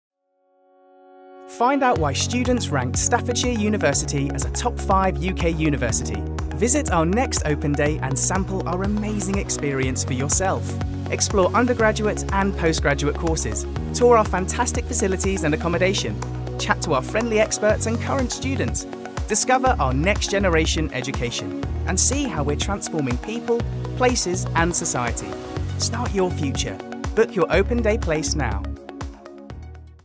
Commercial (Werbung)